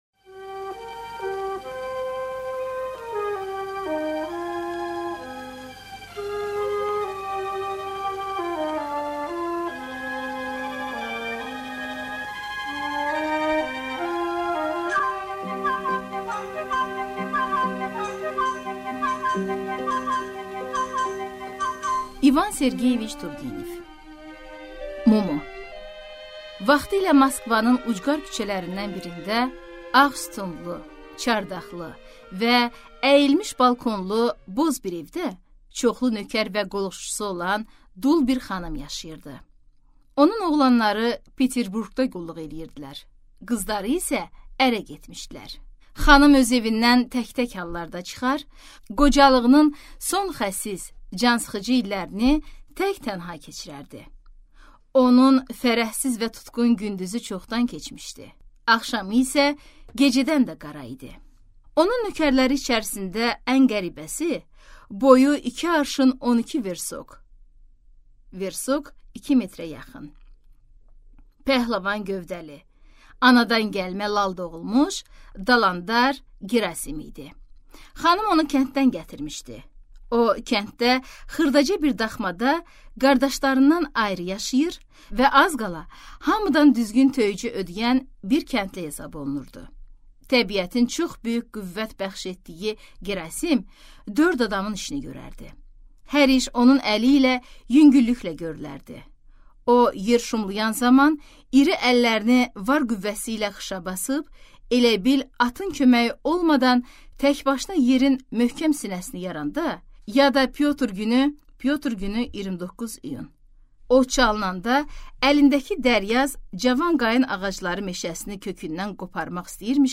Аудиокнига Mumu | Библиотека аудиокниг